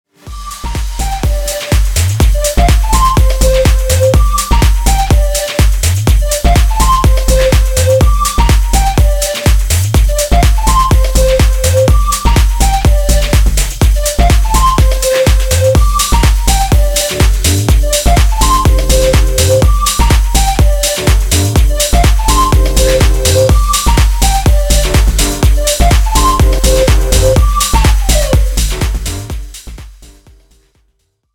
• Качество: 320, Stereo
без слов
Флейта
house
Игра на флейте или чем-то похожем под хаус музку:)